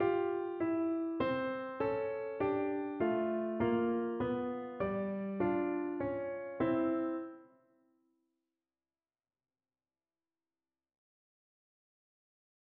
베토벤의 피아노 소나타 E단조의 첫 악장 개발부(development section)에서 베토벤은 주요 주제 중 하나에 "훌륭한 대위법"을 추가하여 이러한 영향을 보여준다.[11]
베토벤 피아노 소나타 작품 90, 첫 악장 110-113 마디